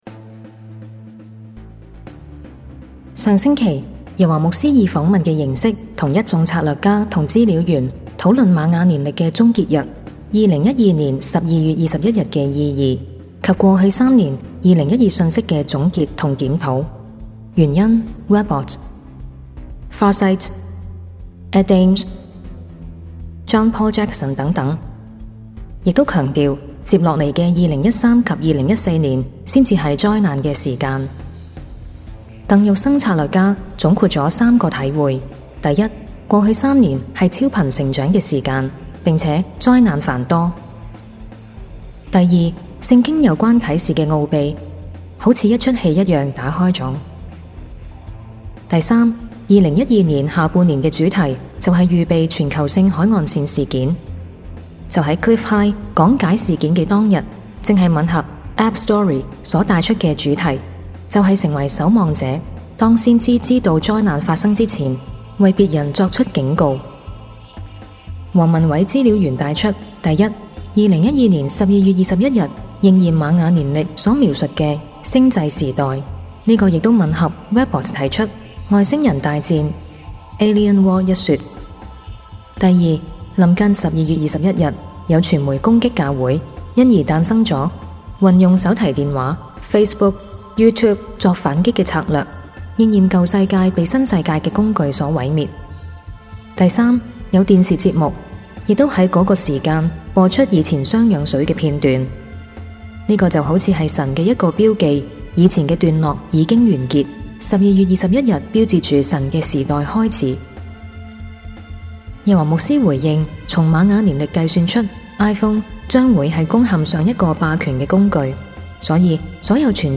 錫安教會主日信息：2012 榮耀盼望 vol. 157 (mp3 純聲音檔)